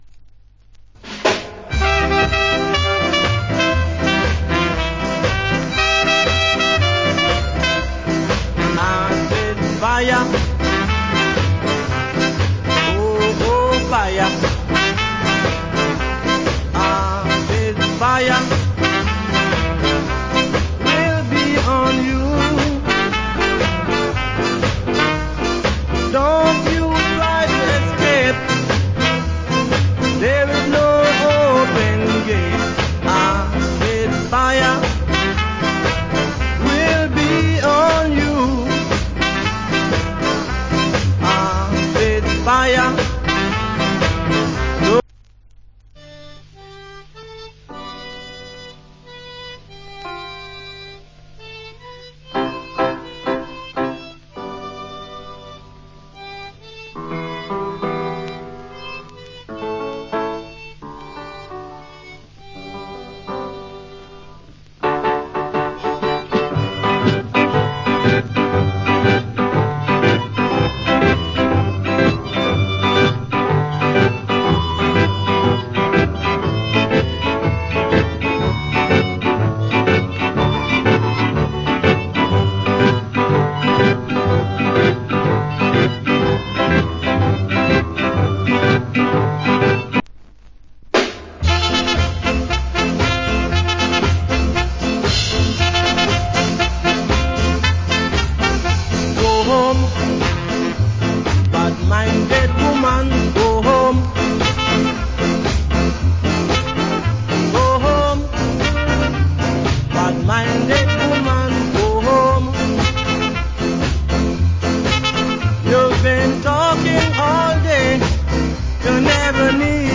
Nice Ska Vocal & Inst.